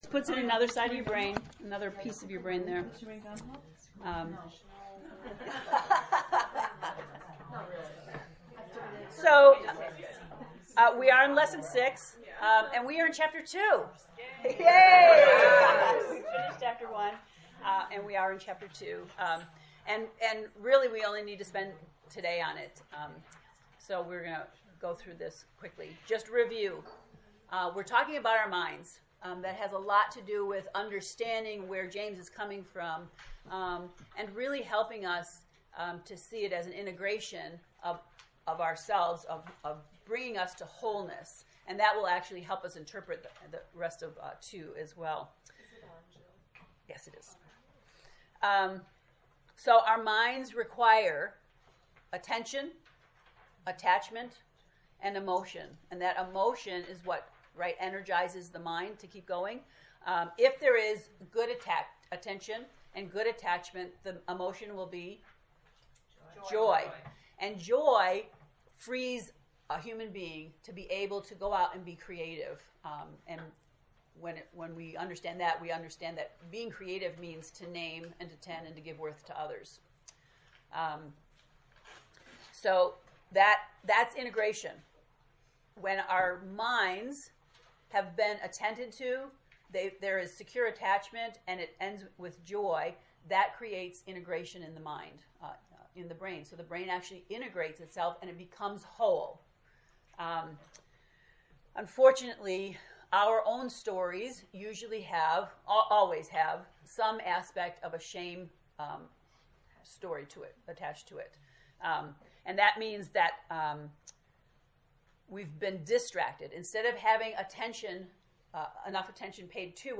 To listen to the lesson 6 lecture, click below: